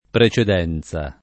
[ pre © ed $ n Z a ]